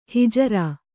♪ hijarā